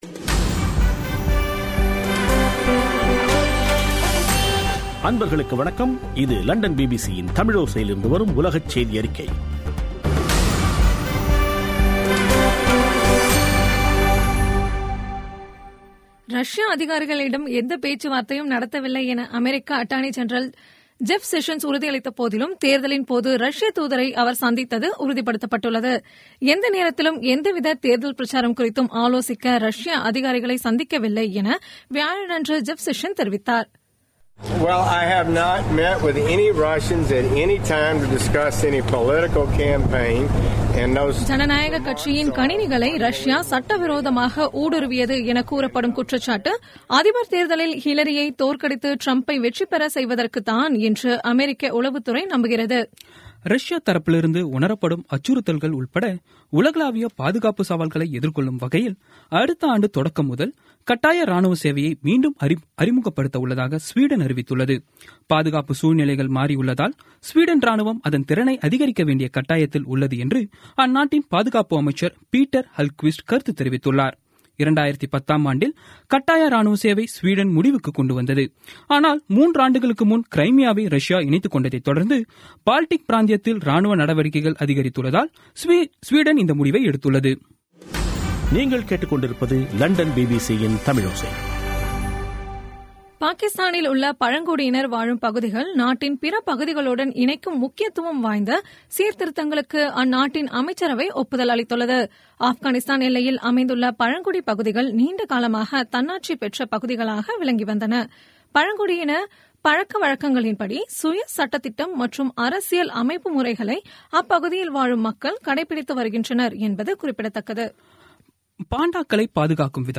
பிபிசி தமிழோசை செய்தியறிக்கை (02/03/17)